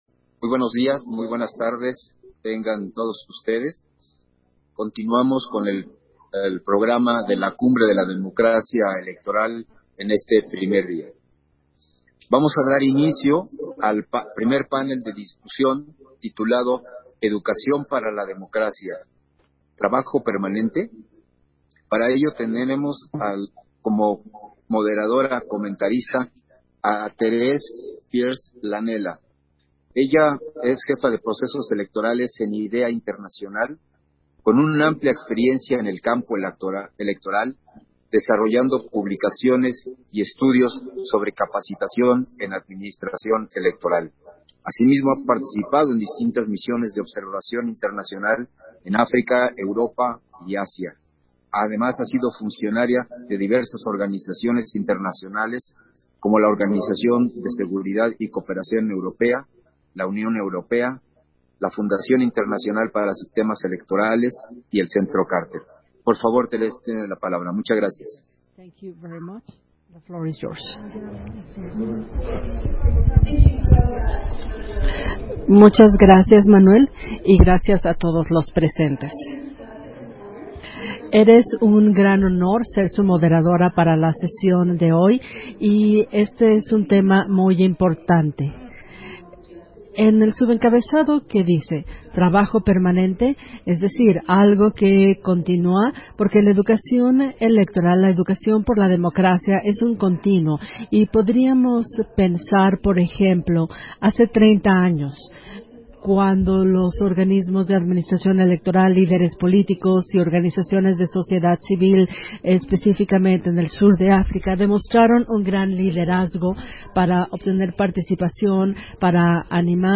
Versión estenográfica del primer panel de discusión: Educación para la democracia ¿trabajo permanente?, en el marco de la Cumbre Global de la Democracia Electoral